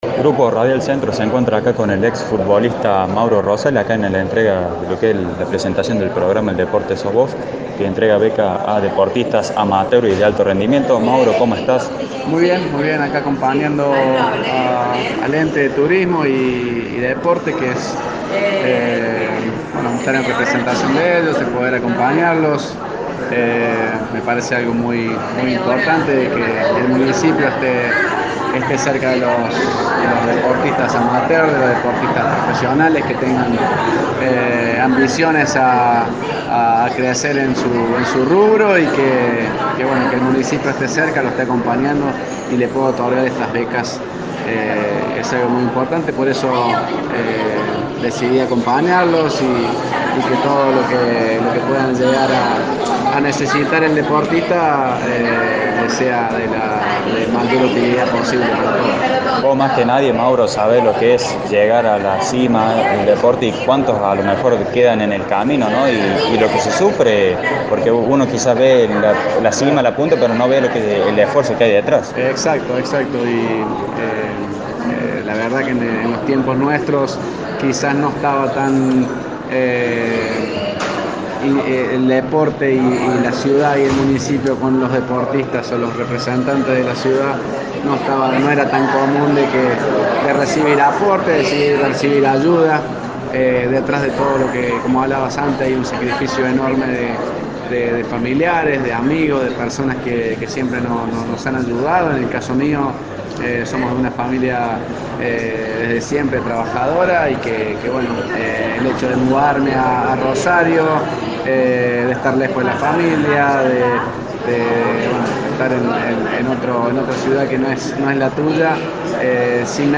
Estuvimos dialogando con Mauro Rosales, en el marco del programa Deporte Sos Vos que entrega de becas para deportistas amateur y de alto rendimiento que lleva adelante el Ente de Deporte y Turismo de la Municipalidad de Villa María.